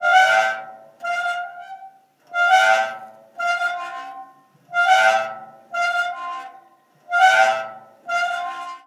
Columpio
balancear chirriar chirrido columpiar columpio
metálico niño ocio ritmo ruido sonido
Sonidos: Acciones humanas